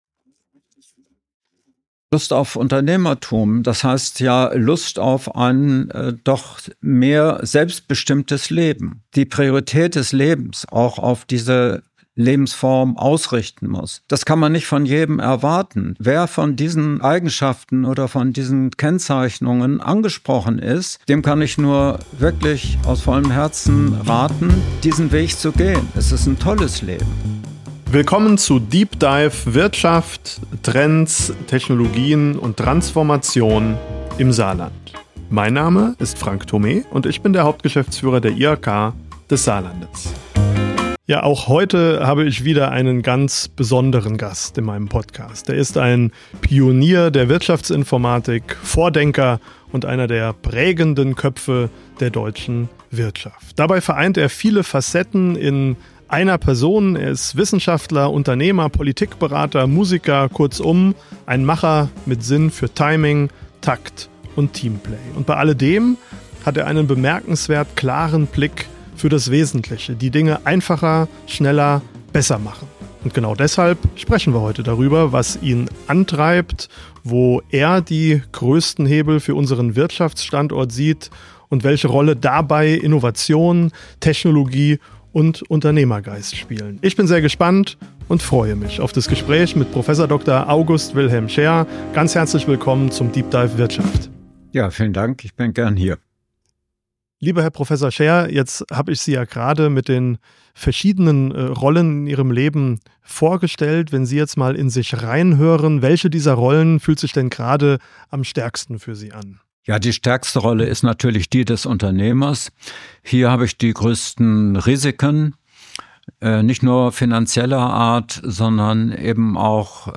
Ein Gespräch über Mut, Anpassungsfähigkeit und die Lust auf Selbstbestimmung – von den Anfängen der Wirtschaftsinformatik über die Lehren aus dem Silicon Valley bis hin zur Frage, wie Jazz und Management zusammenpassen.